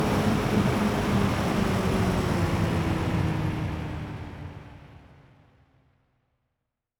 pgs/Assets/Audio/Sci-Fi Sounds/Mechanical/Engine 8 Stop.wav at master
Engine 8 Stop.wav